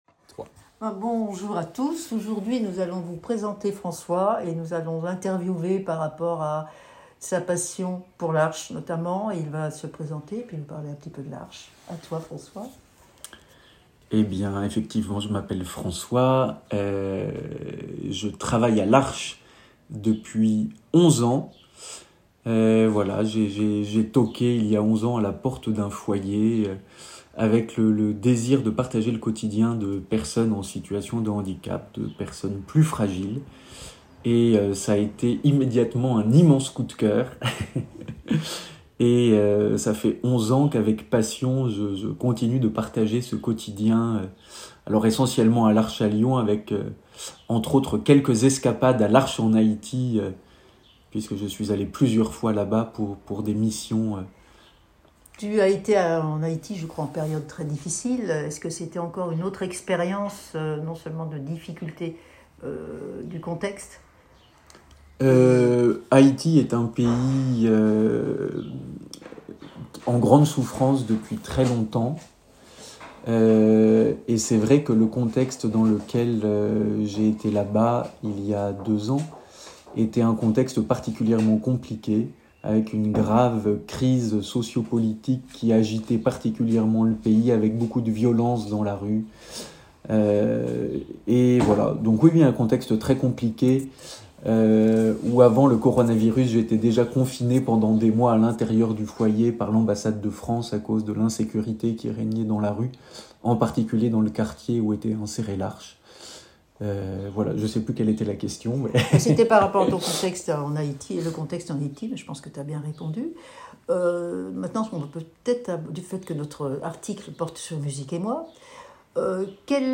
Ce numéro de Musique Emoi commencera exceptionnellement par un interview, il suffira d’utiliser le lecteur ci-dessous.